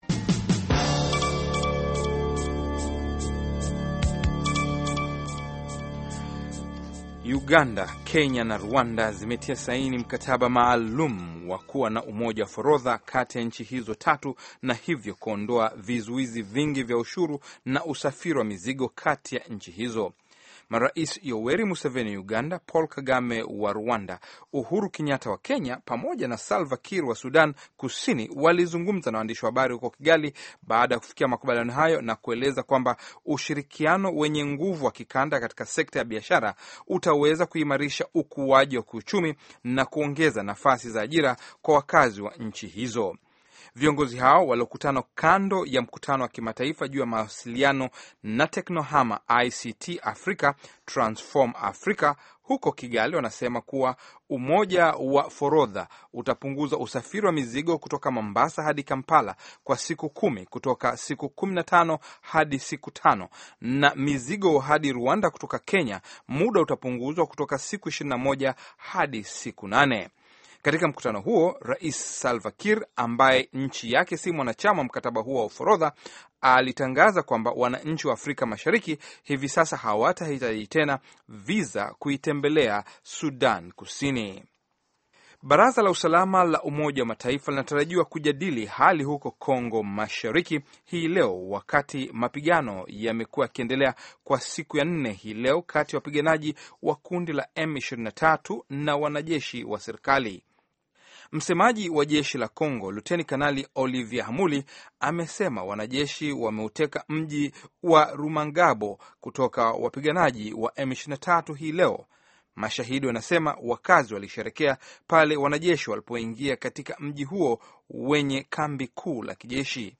Taarifa ya Habari VOA Swahili - 6:40